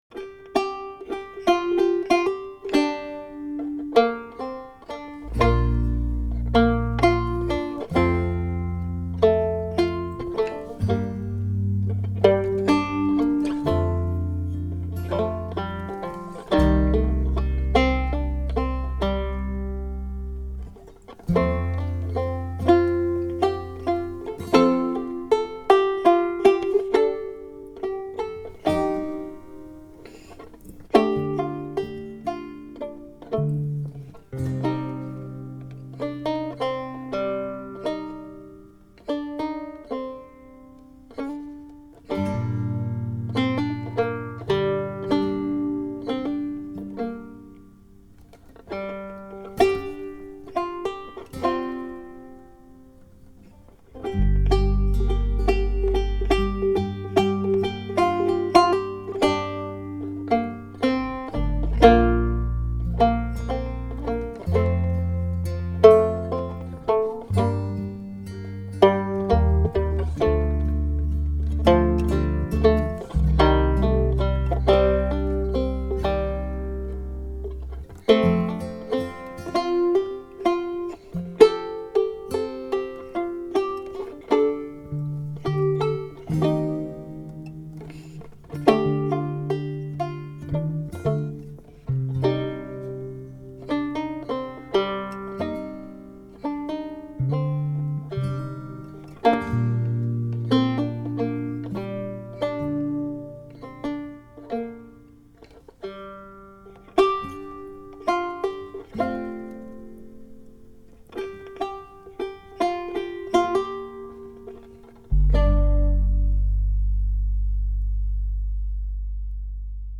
Instrumentals for Banjo